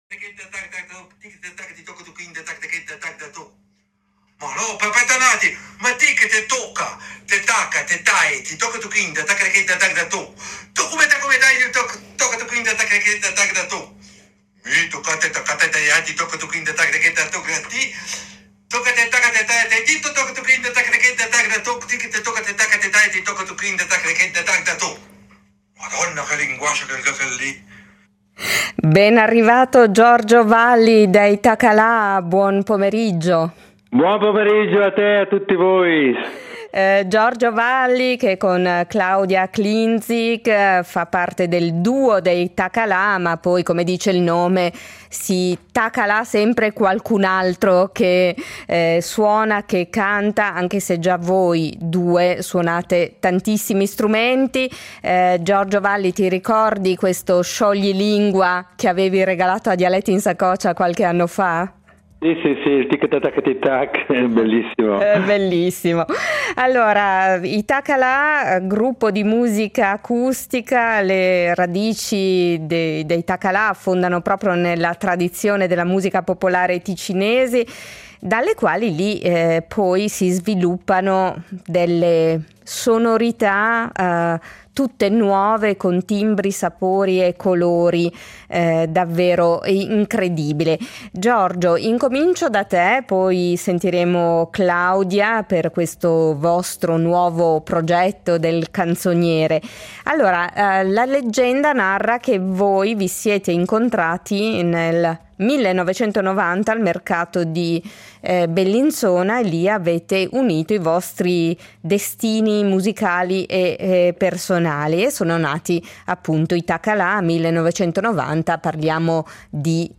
violino e voce
chitarra e voce